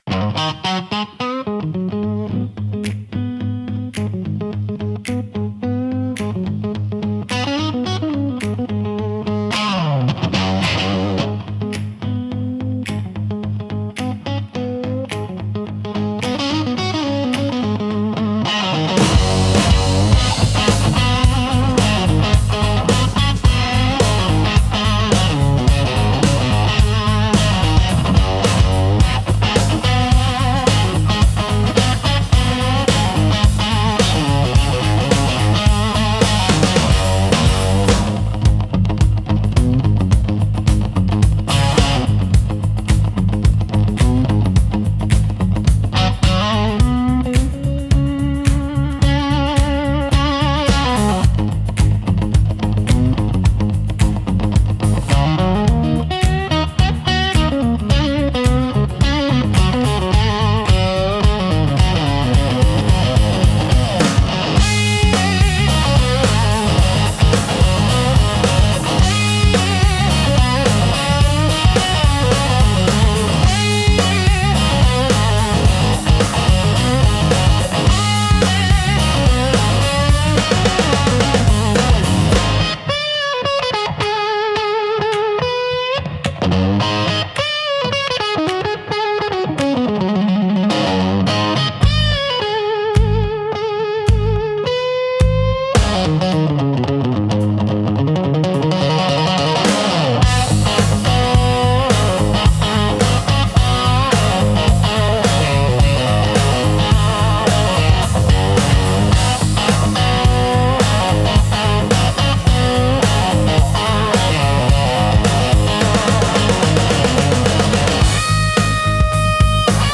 Instrumental - The Getaway in Open G